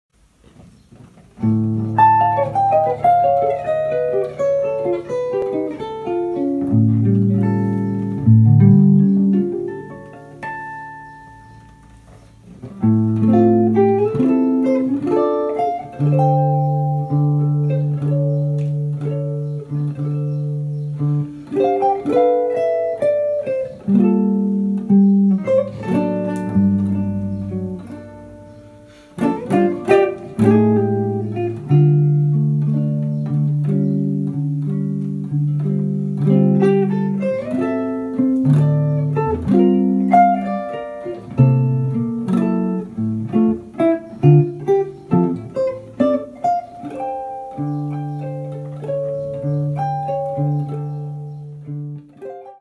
Stemningsfuld guitarmusik til din fest!